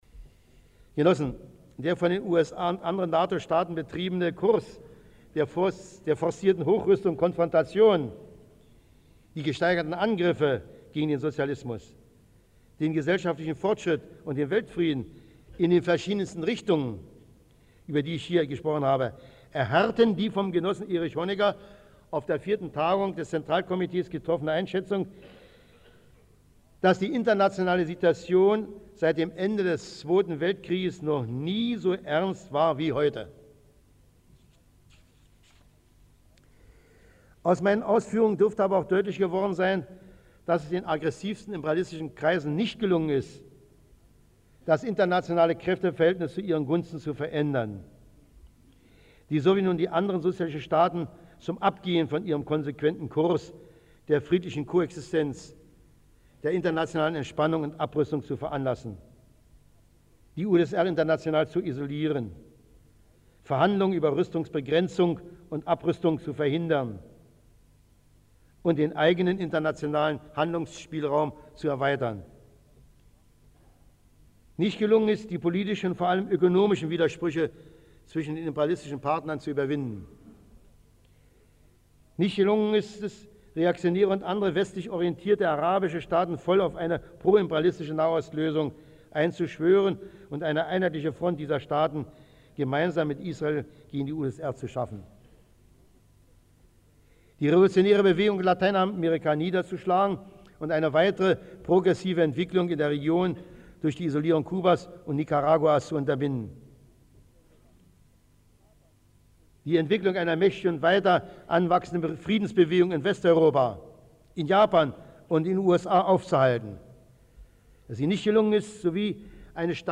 Stasi-Minister Erich Mielke berichtete seinen Mitarbeitern 1982 in einer Rede von der aktuellen Lage. Nachdem er im ersten Teil bereits einige Probleme angesprochen hatte, ging er anschließend noch einmal dezidiert auf die angespannte Lage der DDR-Volkswirtschaft ein.
Rede Erich Mielkes auf einer Tagung der SED-Kreisleitung im Ministerium für Staatssicherheit (Teil 2)